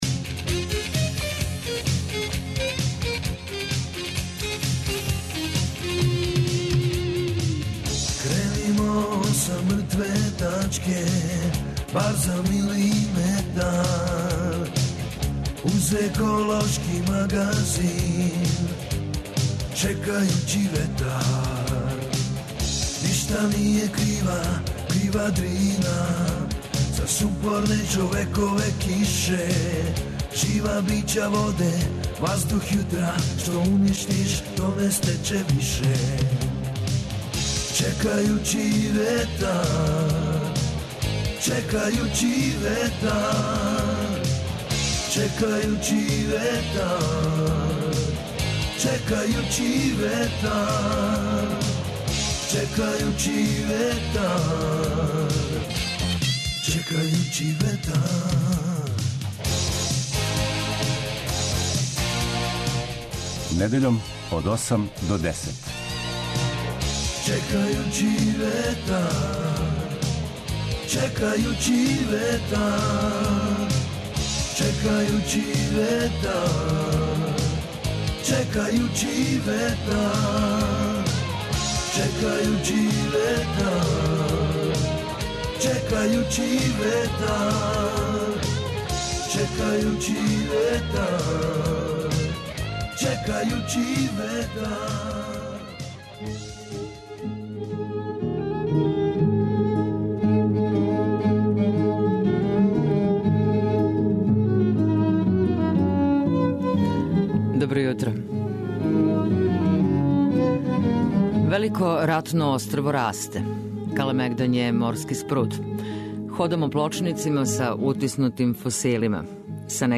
Еколошки магазин